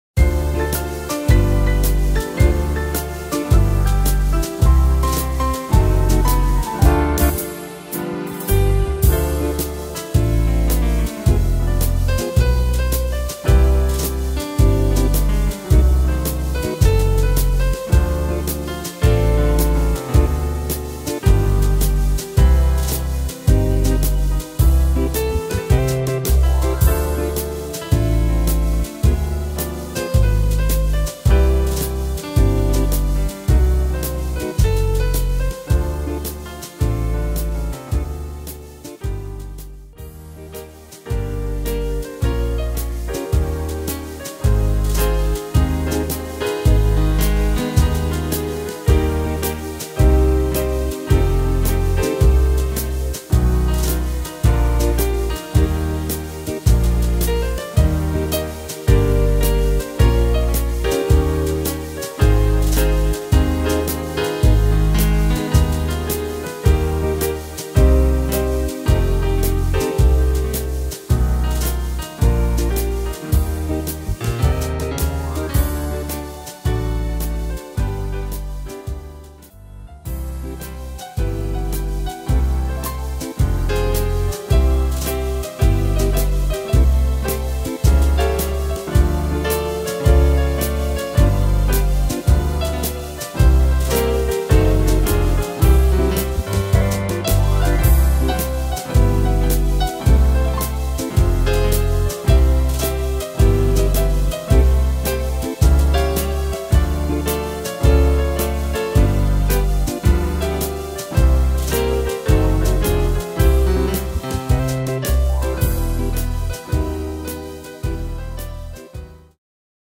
Tempo: 108 / Tonart: Dm